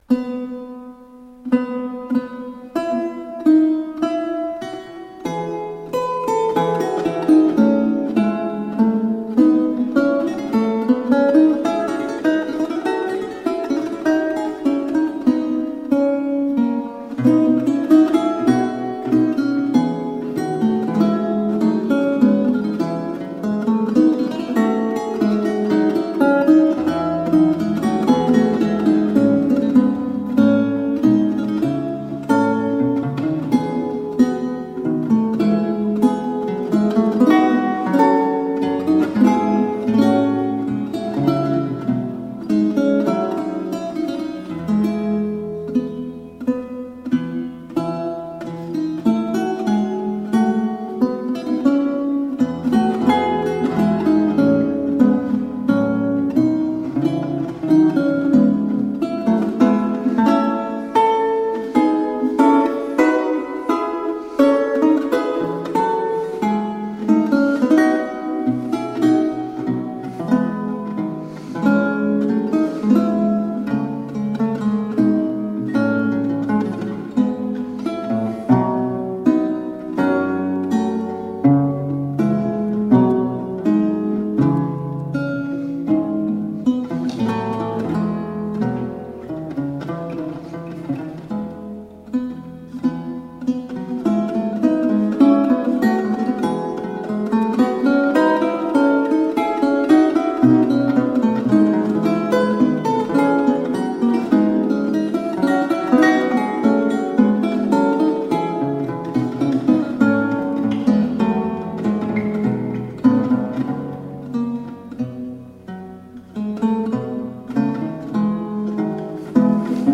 Solo lute of the italian renaissance..